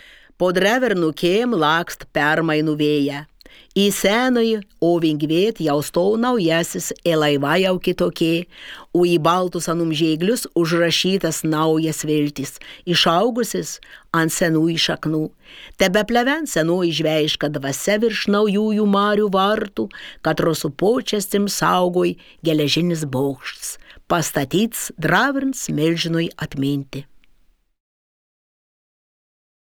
Pasiklausyk šišioniškai